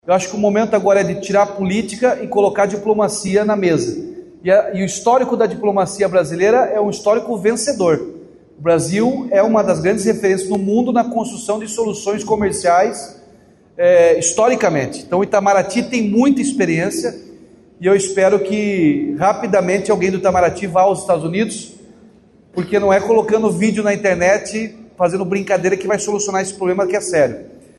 Diante da situação, o governador Ratinho Junior (PSD) defendeu uma atuação mais enérgica do Ministério das Relações Exteriores para evitar prejuízos à economia. Ele falou sobre o tema durante um evento na Associação Comercial do Rio de Janeiro, na sexta-feira (25).